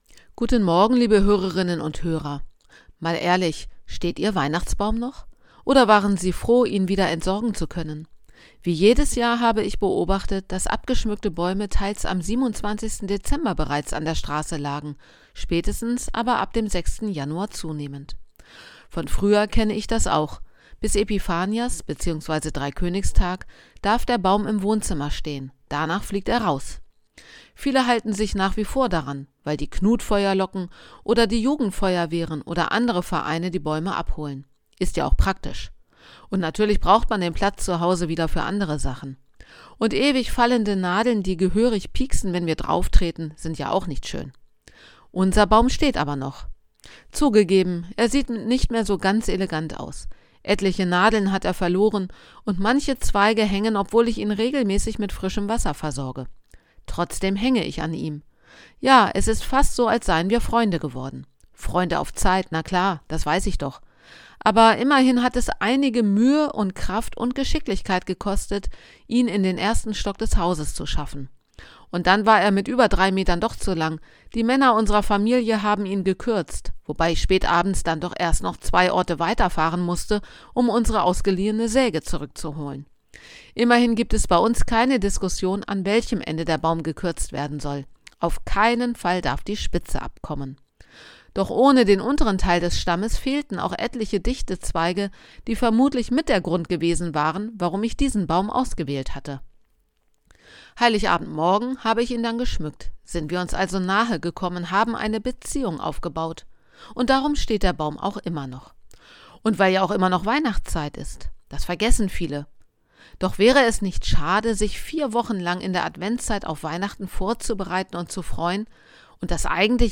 Radioandacht vom 22. Januar